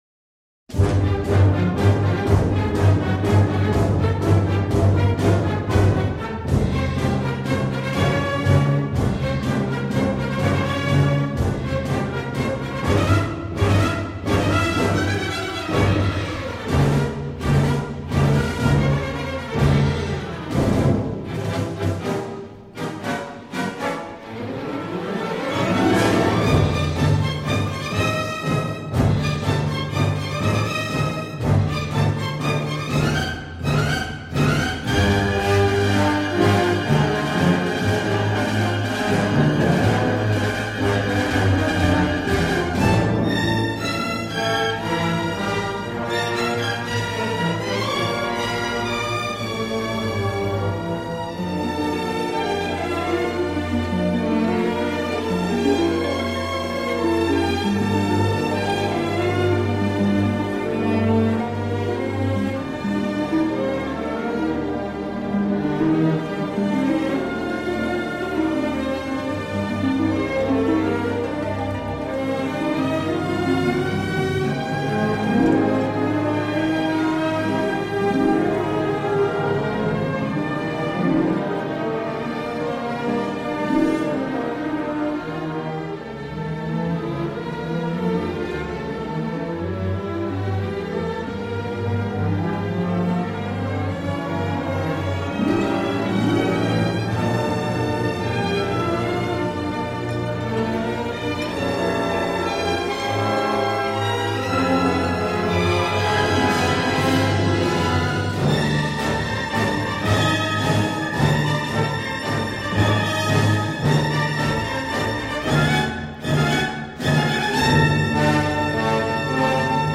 un cachet orchestral formidable et plein de force
mais son style ample et passionné est un régal.
Dommage, le son est un brin étouffé par instants.